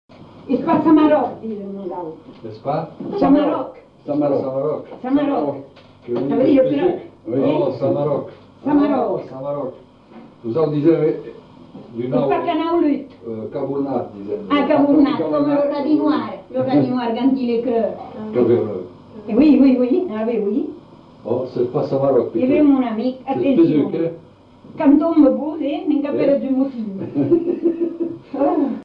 Aire culturelle : Marmandais gascon
Lieu : Tonneins
Genre : forme brève
Effectif : 1
Type de voix : voix de femme
Production du son : récité
Classification : locution populaire